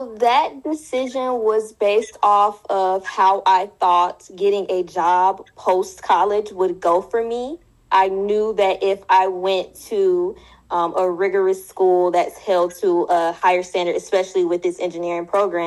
Demographics: Black woman, 24 (Generation Z)[23]